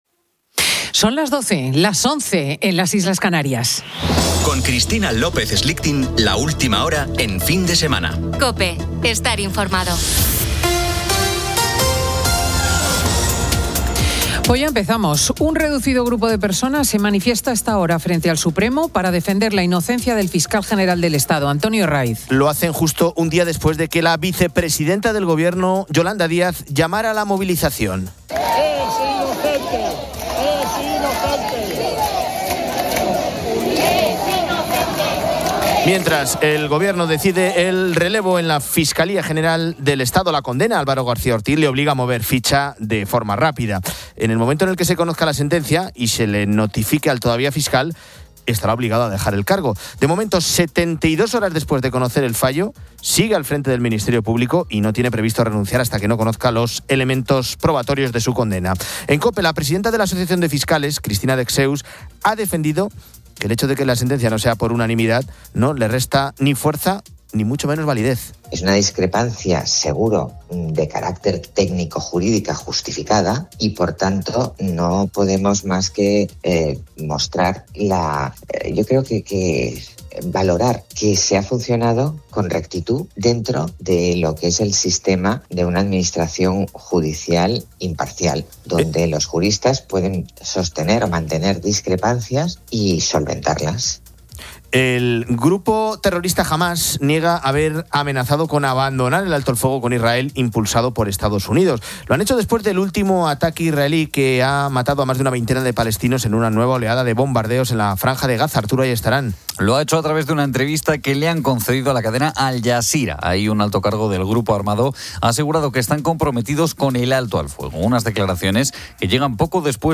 Nos encontramos en la Sacristía de la Basílica de San Juan de Dios en Granada, de la mano de Óptima Cultura.